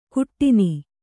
♪ kuṭṭini